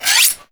knifesharpener2.wav